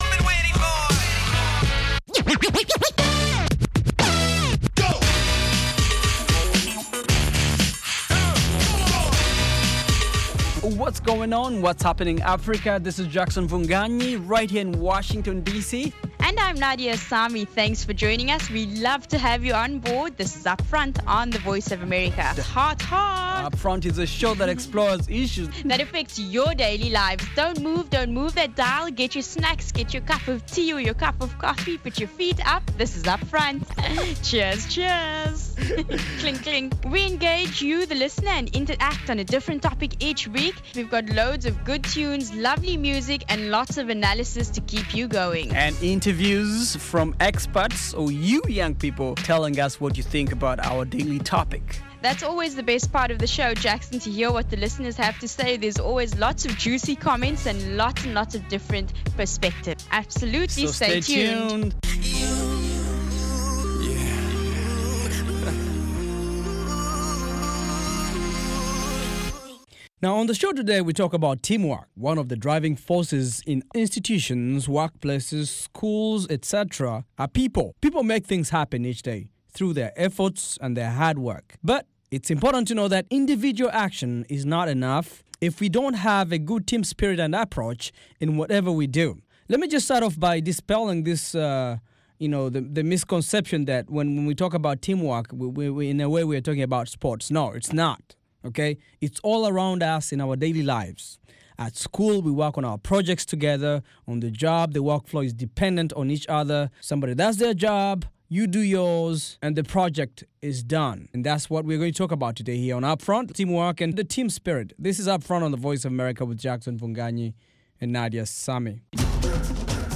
On this fresh, fast-paced show, co-hosts